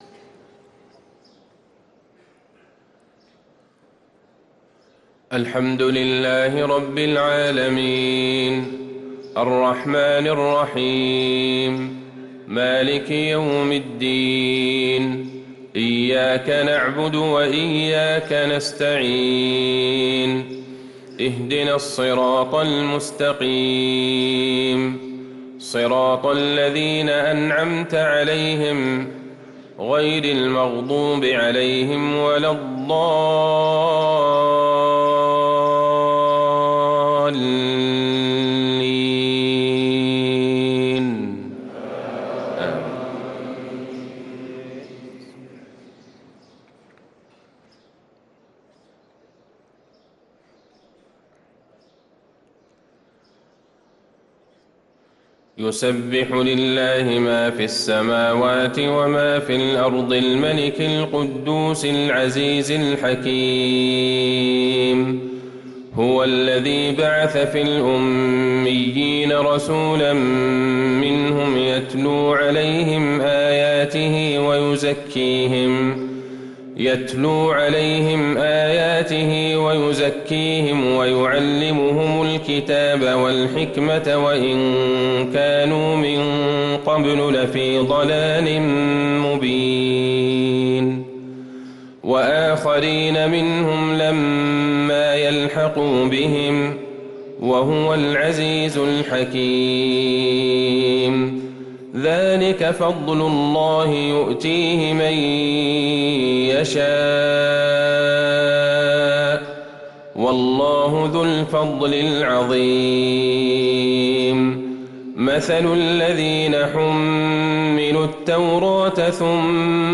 صلاة الفجر للقارئ عبدالله البعيجان 13 رجب 1445 هـ
تِلَاوَات الْحَرَمَيْن .